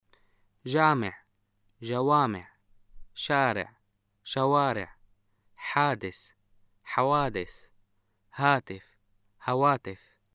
シリアのアラビア語 文法 名詞の複数形：例文
[ʒaameʕ (ʒawaameʕ), ʃaareʕ (ʃawaareʕ), ħaades (ħawaades), haatef (hawaatef)]